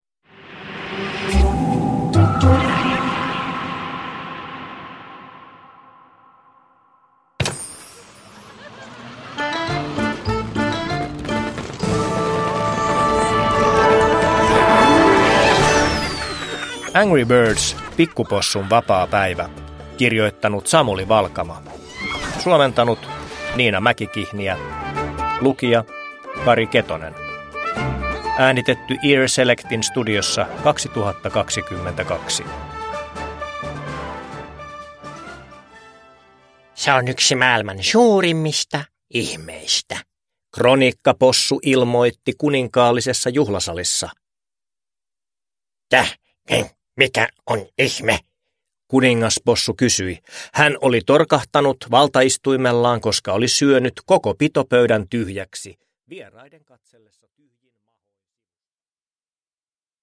Angry Birds: Pikkupossun vapaapäivä (ljudbok) av Samuli Valkama